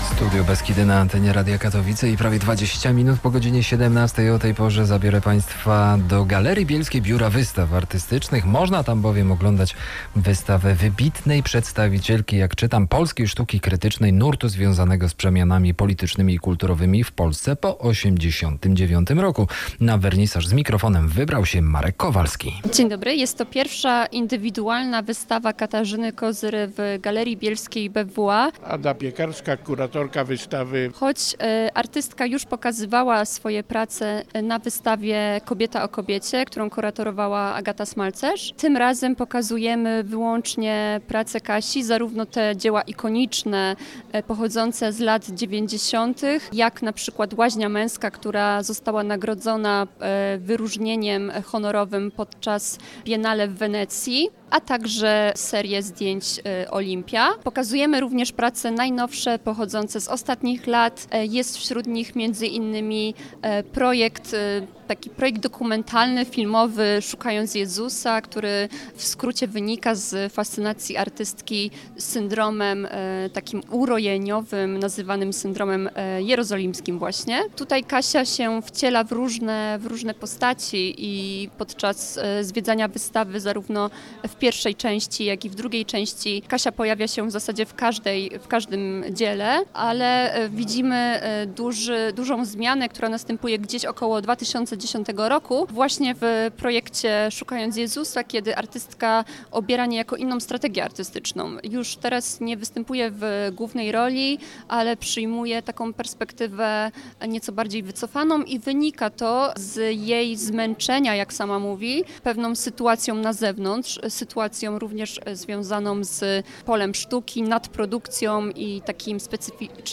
audycja o wystawie Katarzyny Kozyry, BYŚ KIMŚ, KIM SIĘ NIE JEST, styczeń